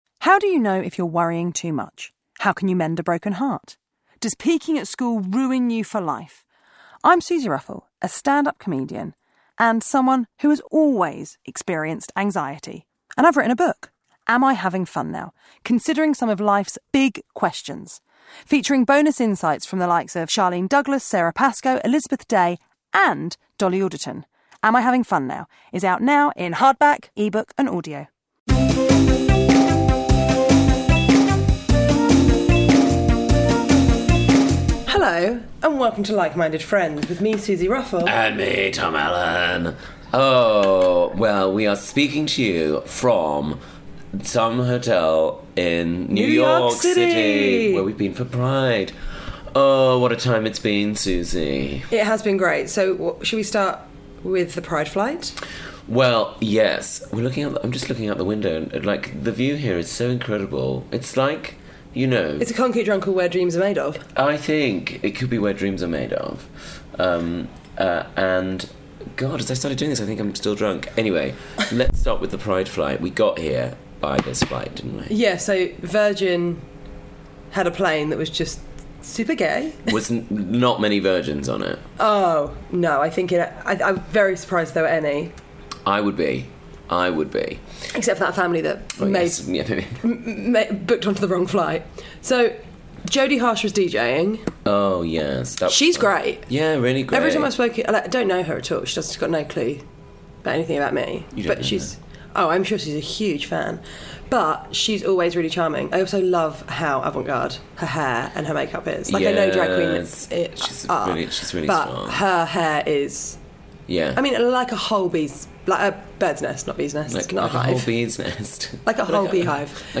Did we mention we are at New York Pride?